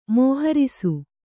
♪ mōharisu